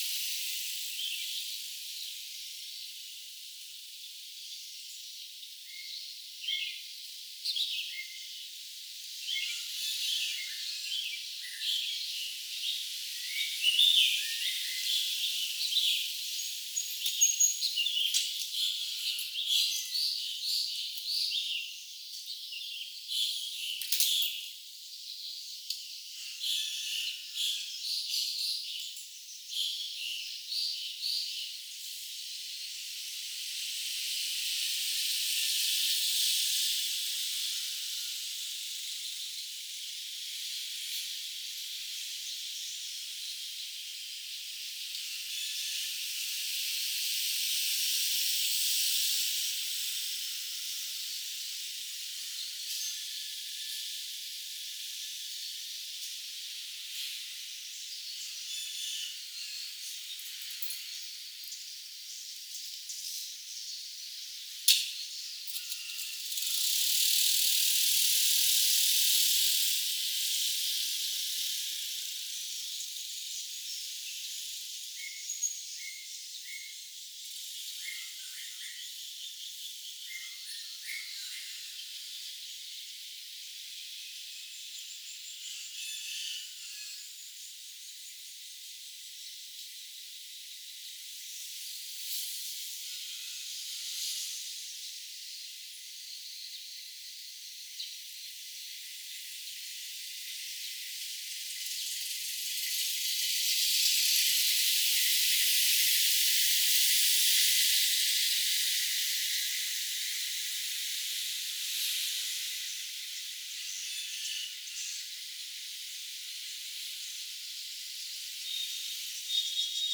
Demonstration soundscapes
biophony
anthropophony